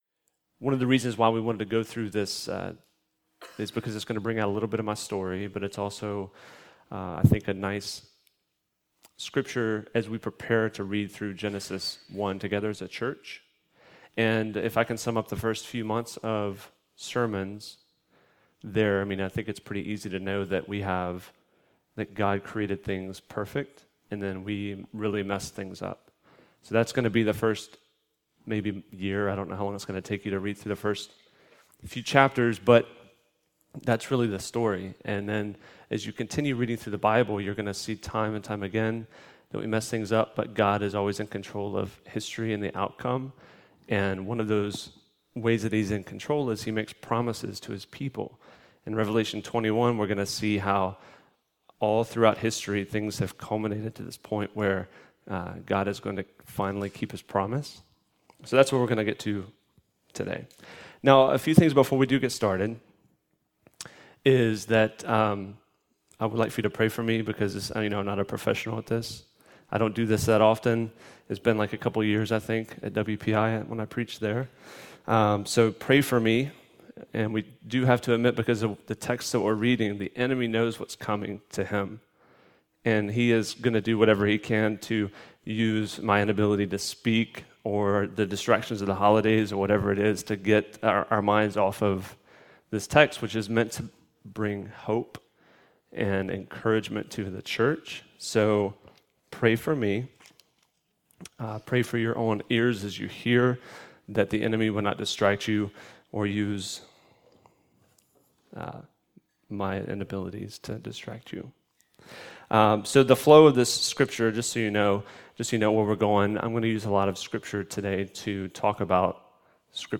Guest Preacher